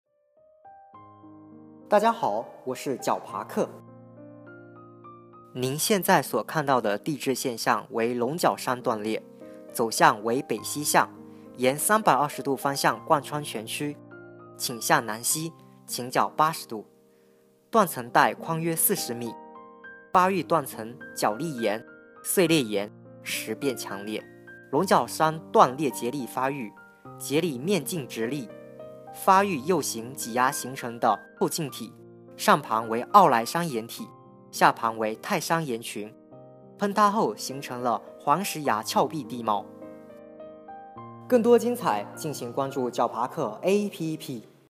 龙角山断裂----- 石破天惊 解说词: 断裂走向为北西向，沿320°方向贯穿全区。